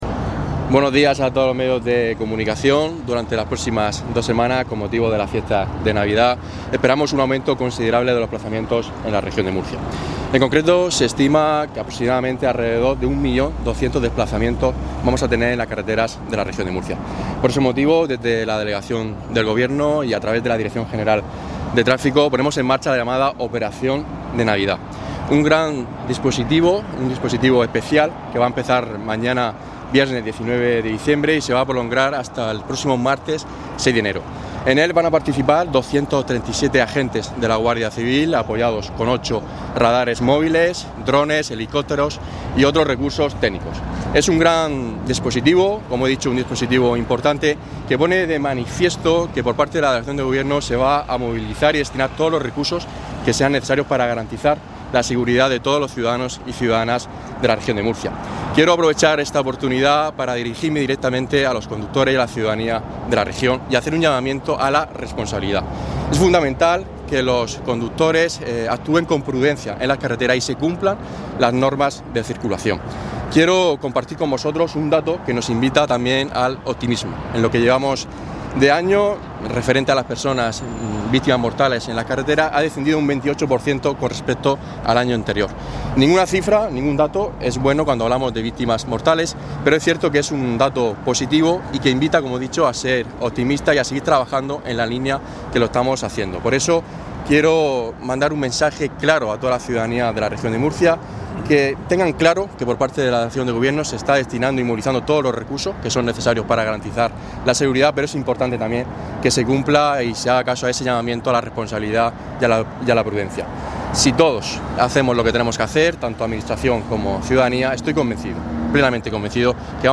Declaraciones de Francisco Lucas